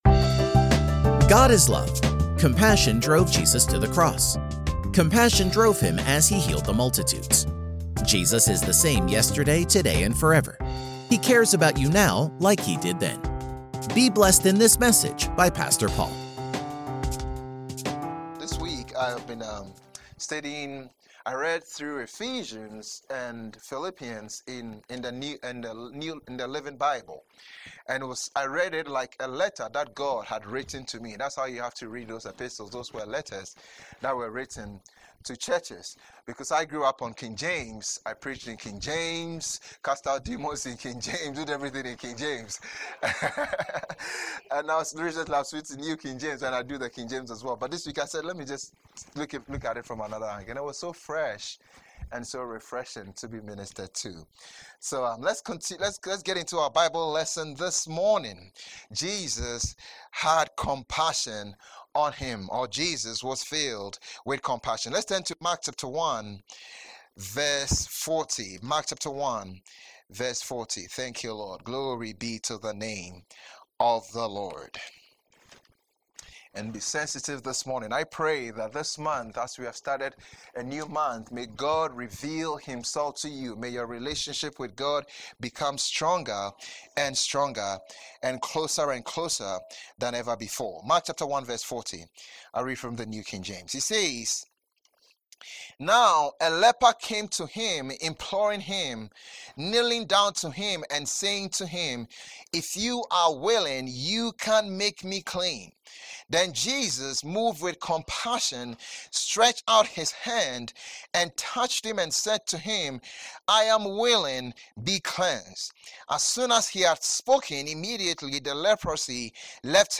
Post category: Sermon